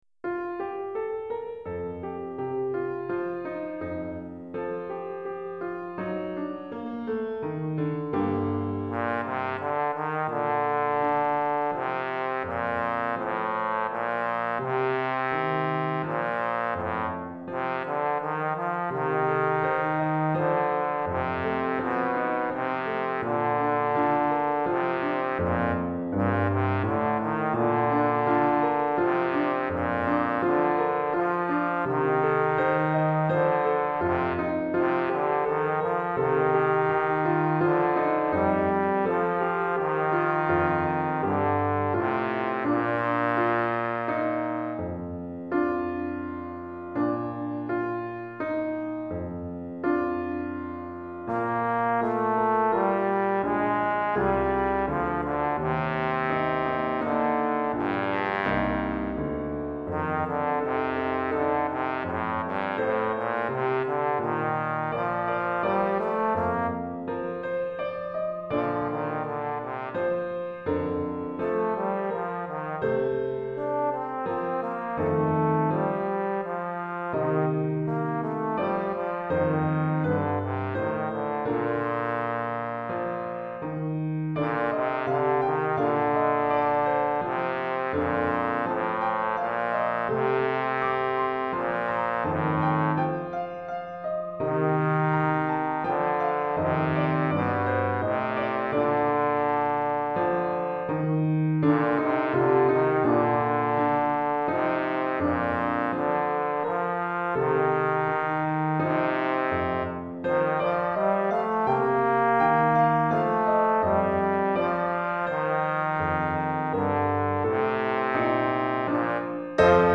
Trombone et Piano